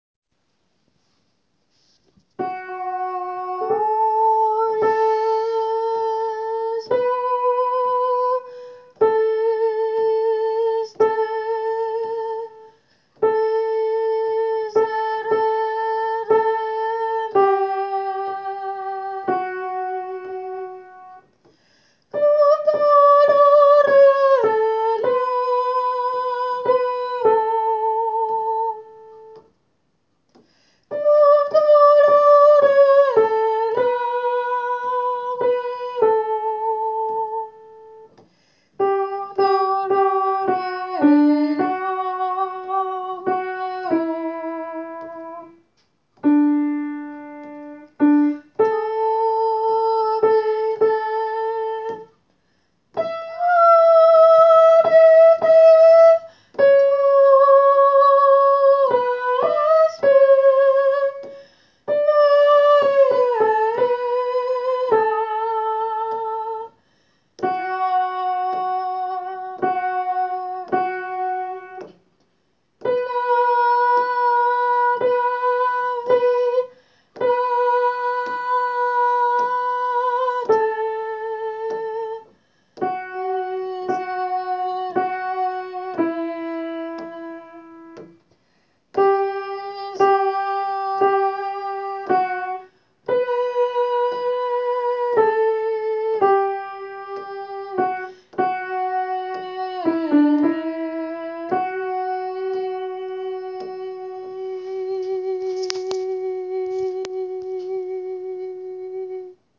Soprano :
jesu-christe-soprano.wav